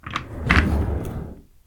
针对桌椅移动嘈杂音效的PPT演示模板_风云办公